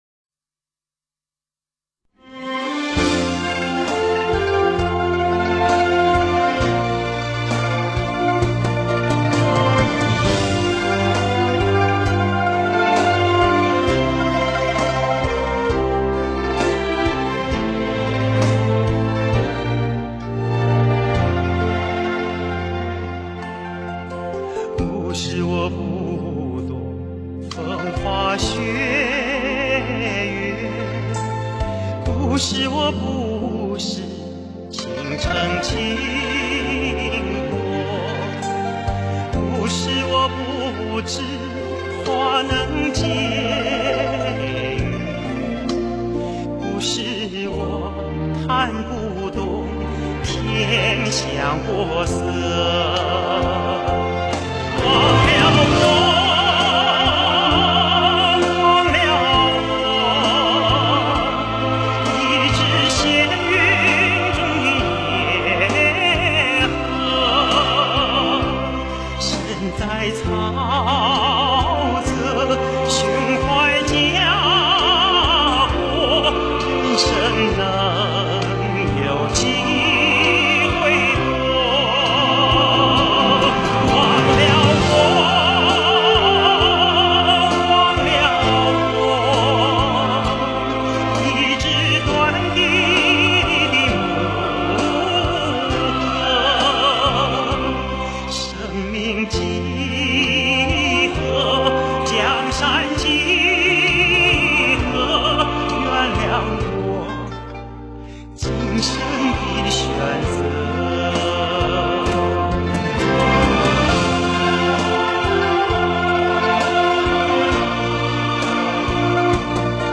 片尾曲）纯原声